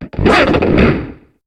Cri d'Ouvrifier dans Pokémon HOME.